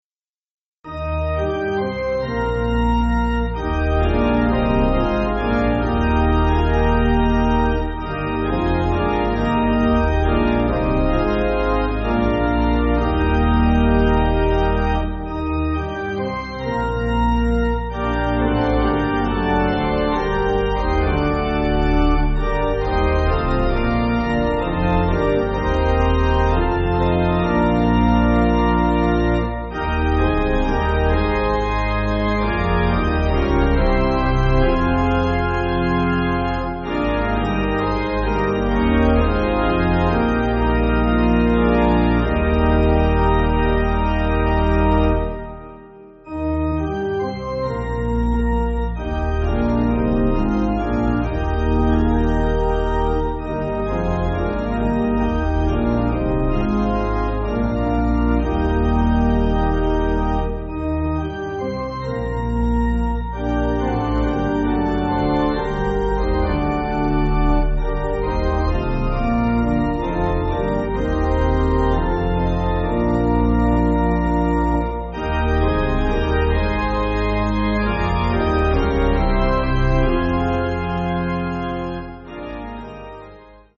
(CM)   4/Eb